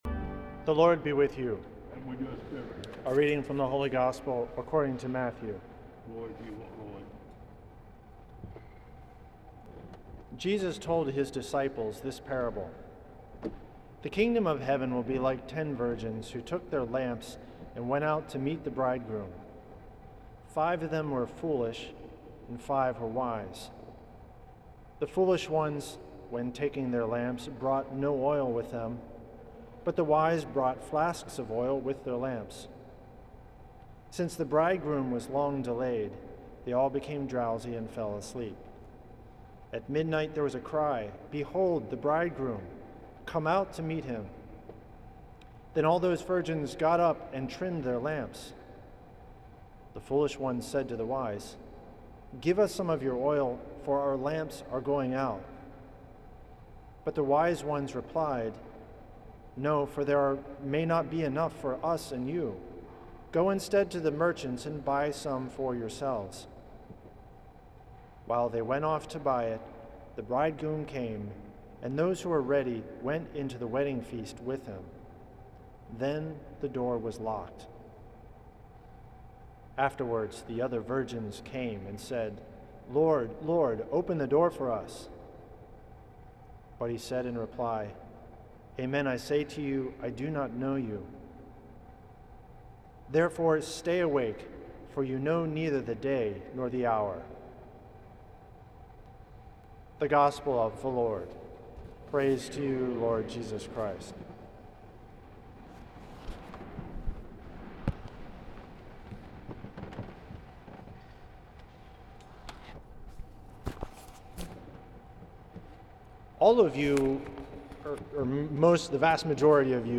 Homily
for the 11th Sunday in Ordinary Time at St. Patrick's Old Cathedral in NYC.